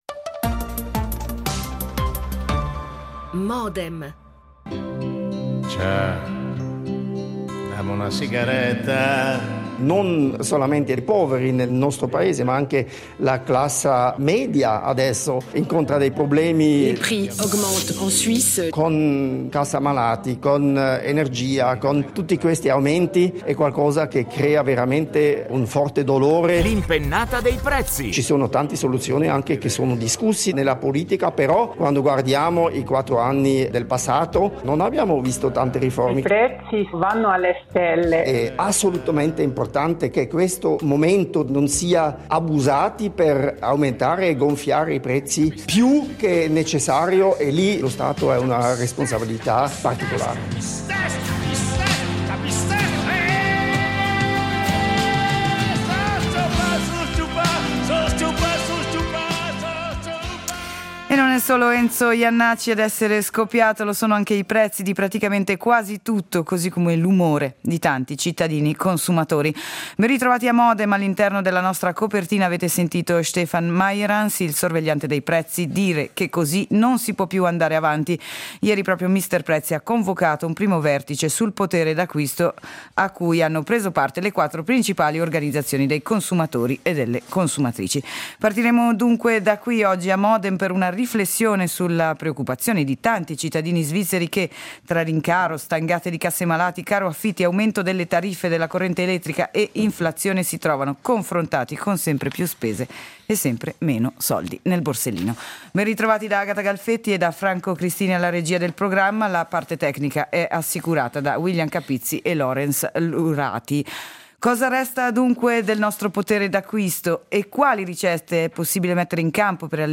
Ne discutiamo con tre ospiti:
L'attualità approfondita, in diretta, tutte le mattine, da lunedì a venerdì